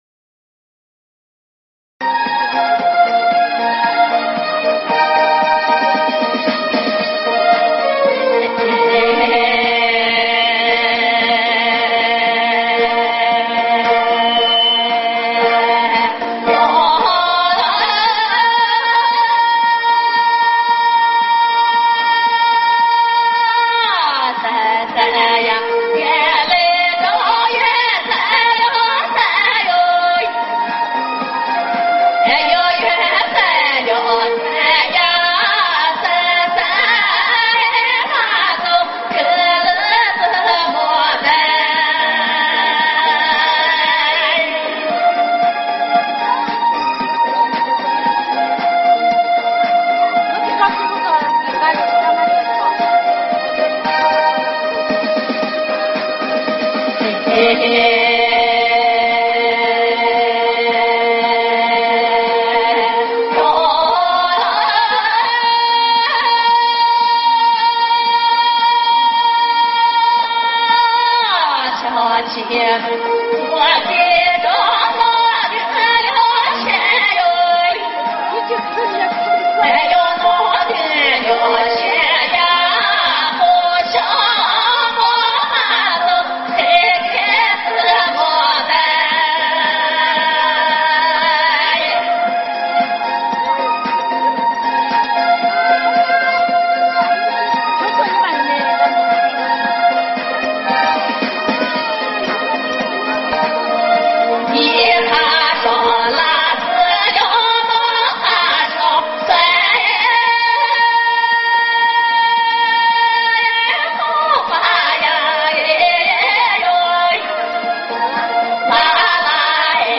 青海花儿